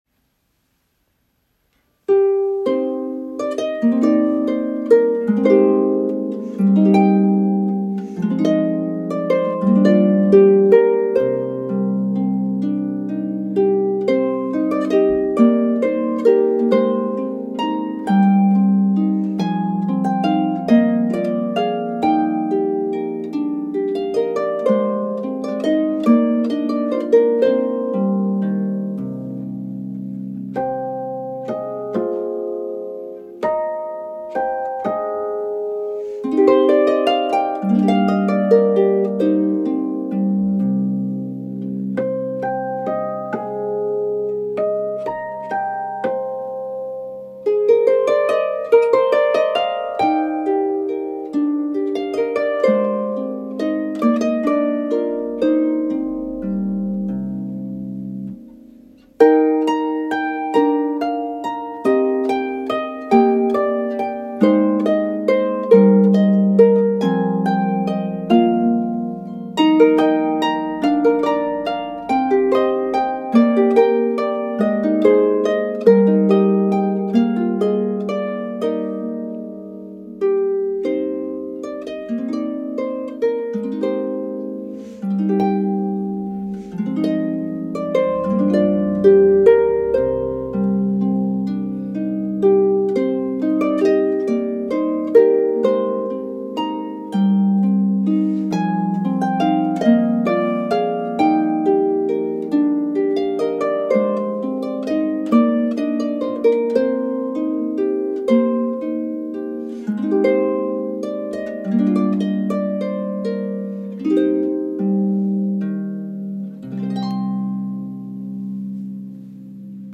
Love the Celtic rhythm and the sweet melody.